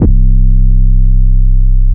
808 [plugg].wav